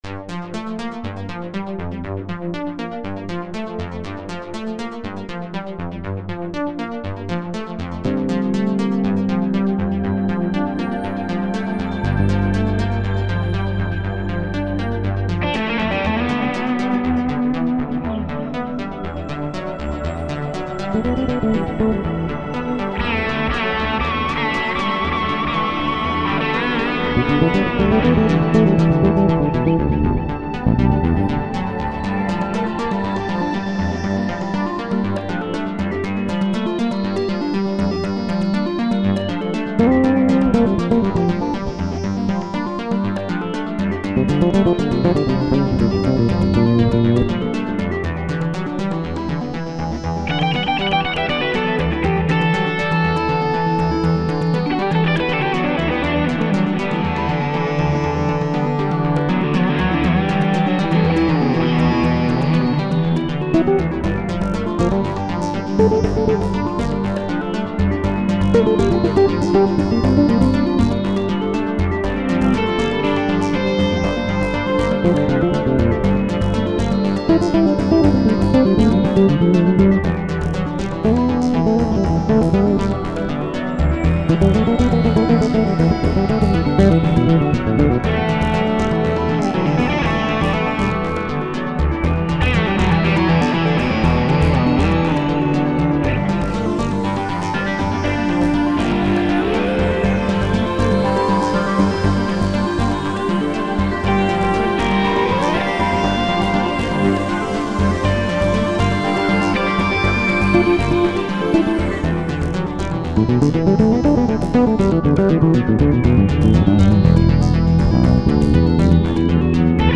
Based on NASA's Voyager deep space missions, the six-movement composition exemplifies how software-based virtual instruments have largely replaced their historical hardware predecessors in the creation of modern music and, to some degree, modern film scores. Embellished with virtuosic electric bass and electric guitar solos